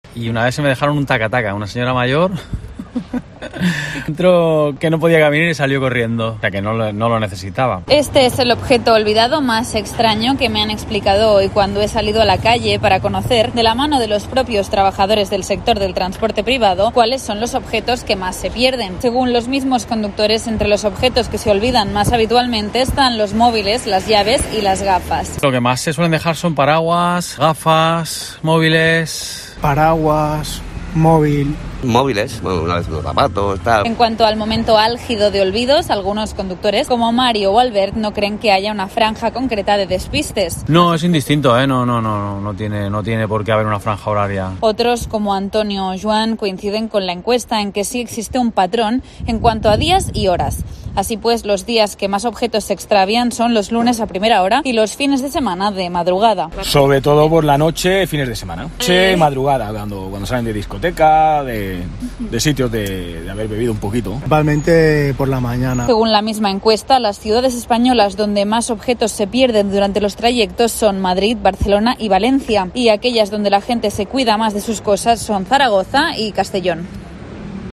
En COPE Cataluña y Andorra hemos salido a la calle para conocer, de la mano de los propios trabajadores del sector del transporte privado, cuáles son los objetos que más es pierden.